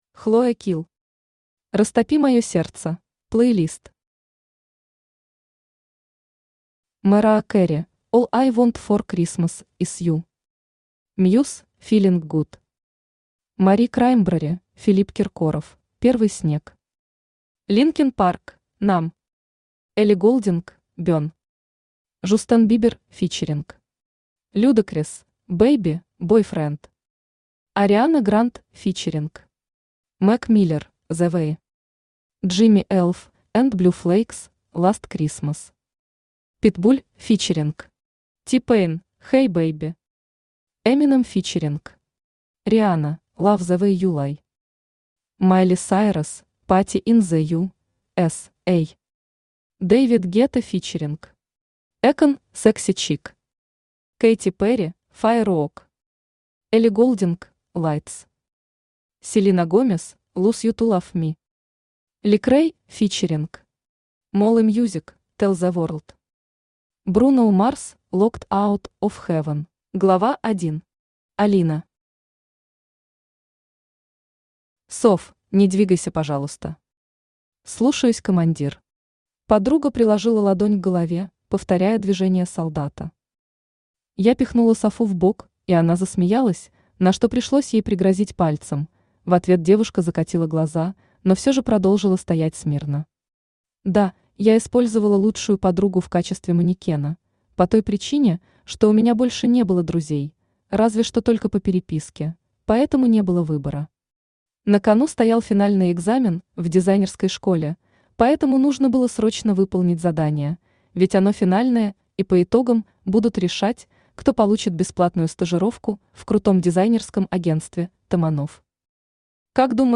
Аудиокнига Растопи мое сердце!
Автор Хлоя Килл Читает аудиокнигу Авточтец ЛитРес.